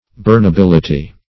burnability - definition of burnability - synonyms, pronunciation, spelling from Free Dictionary